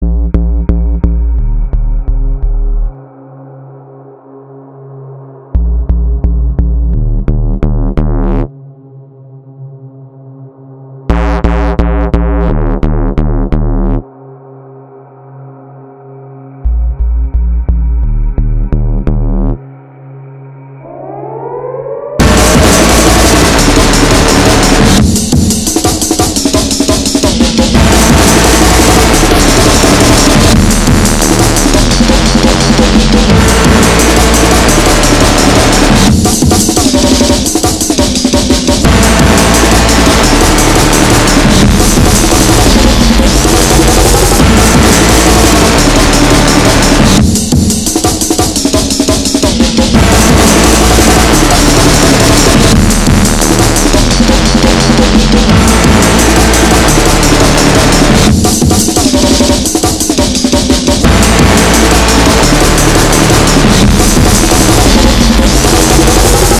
Dark Drum n bass[mp3]
Just had fun with drum n bass.
It's pace goes up way to fast. I liked the ambient part in the beginning though.